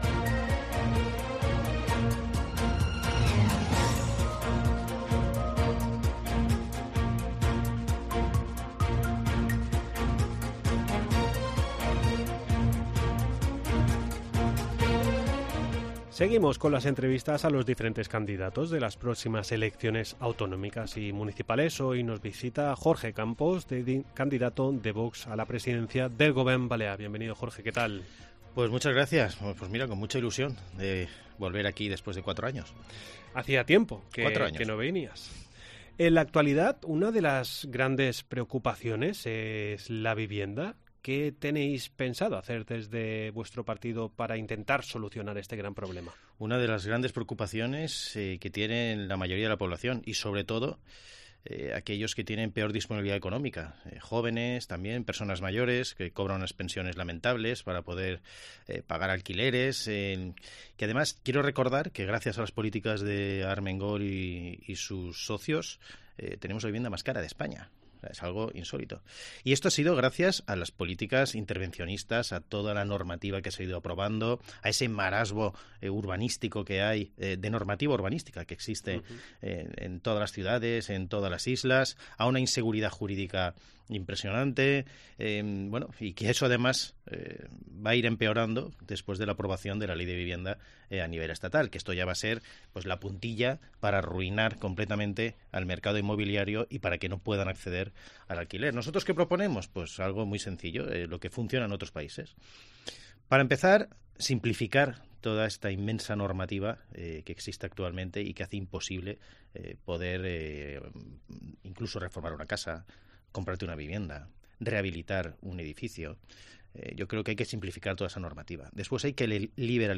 Seguimos con las entrevistas a los diferentes candidatos de las próximas elecciones. Hoy nos visita Jorge Campos, candidato de Vox a la presidencia del Govern 00:00 Volumen Descargar Redacción digital Madrid - Publicado el 20 abr 2023, 15:29 1 min lectura Descargar Facebook Twitter Whatsapp Telegram Enviar por email Copiar enlace "¿Qué está pasando?